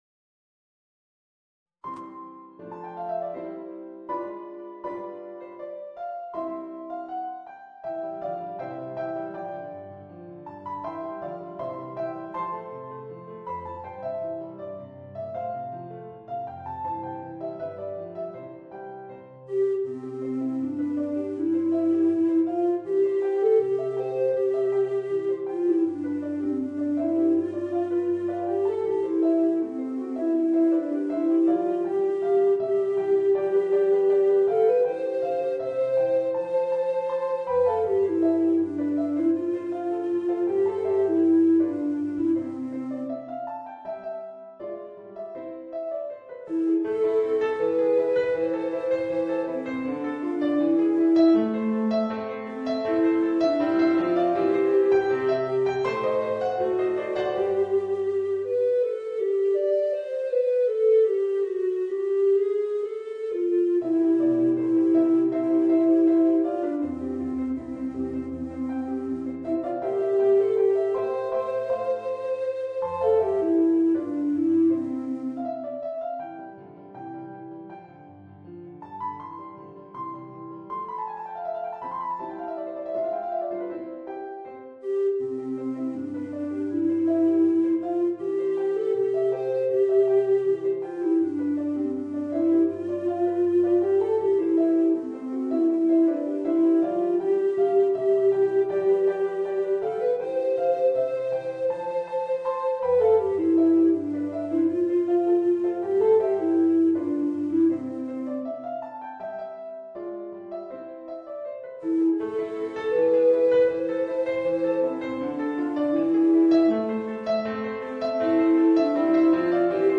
Voicing: Bass Recorder and Piano